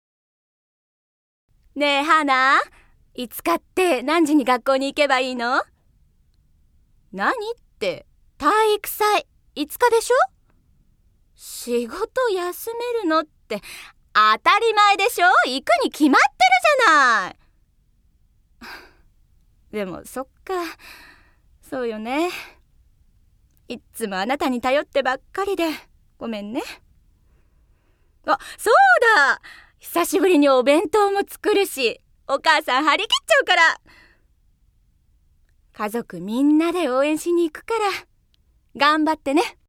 ◆若い母親◆